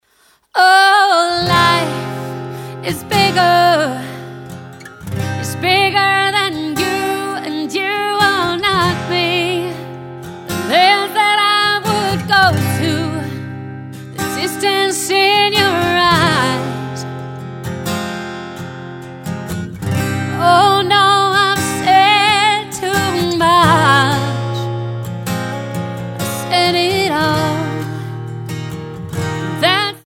--> MP3 Demo abspielen...
Tonart:Fm Multifile (kein Sofortdownload.
Die besten Playbacks Instrumentals und Karaoke Versionen .